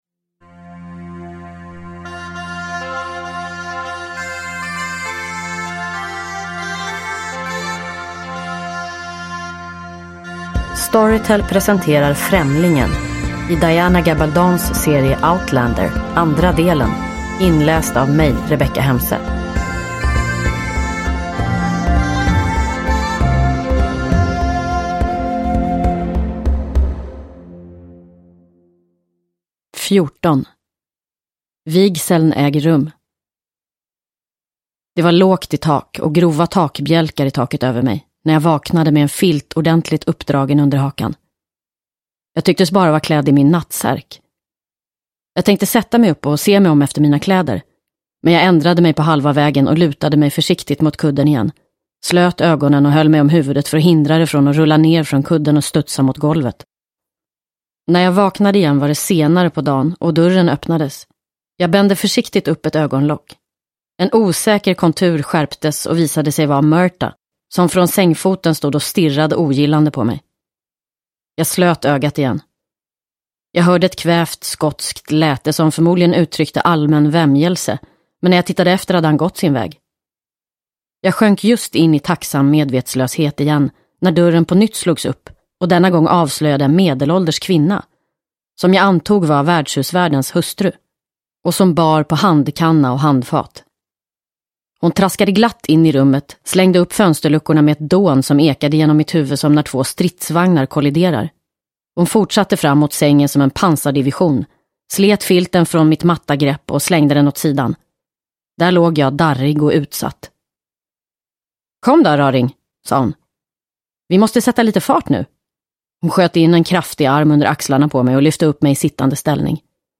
Främlingen - del 2 – Ljudbok – Laddas ner
Uppläsare: Rebecka Hemse